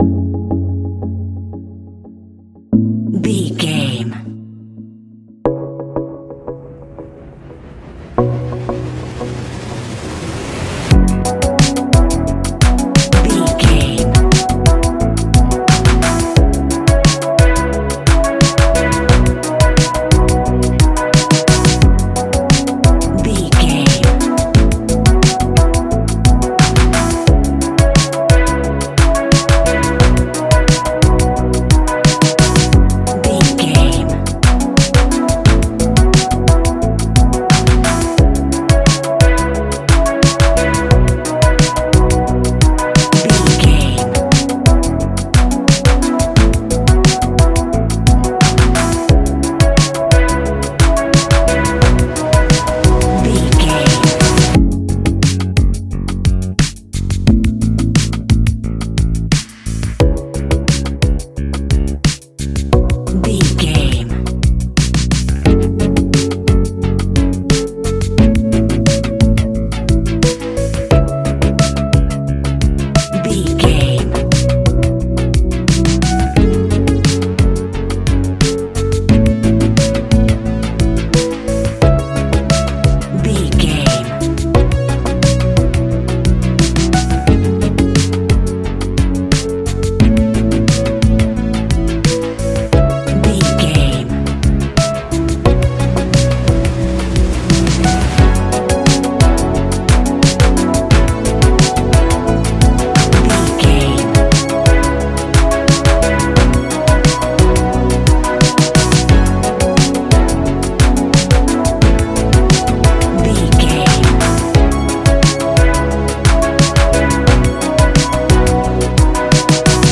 Ionian/Major
F♯
ambient
electronic
new age
downtempo
pads